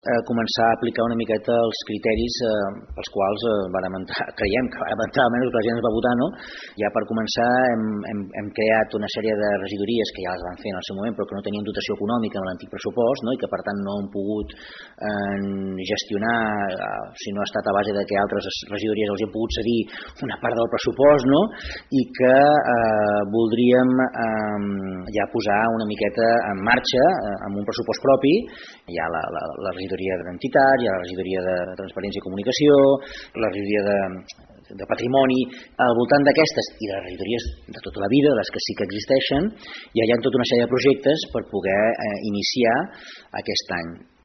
L’alcalde de Palafolls, Francesc Alemany, explica que a partir d’aquest nou pressupost es podran començar a desplegar plenament els projectes amb els que van aconseguir arribar al govern palafollenc.